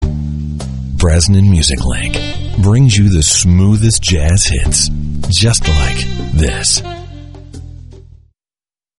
Radio Imaging & Voiceover